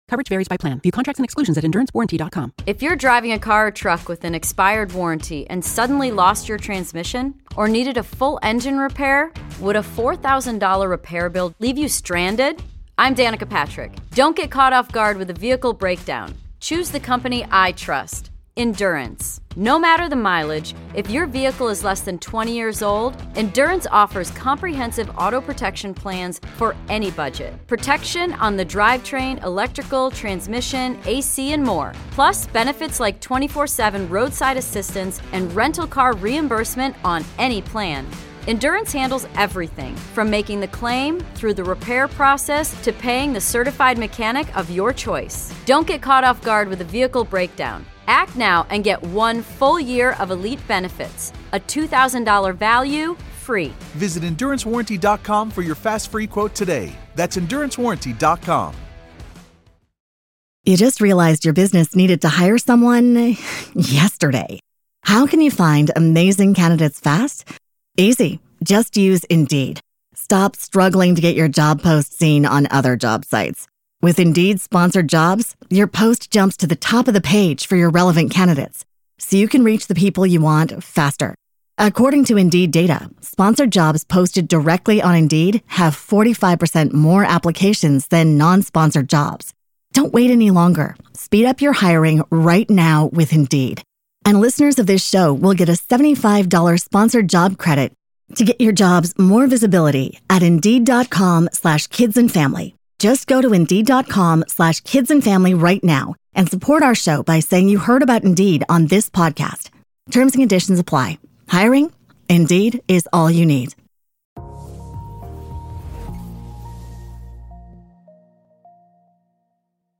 1 How Self-Belief and Resilience Transform Lives - Powerful Motivational Speech 10:45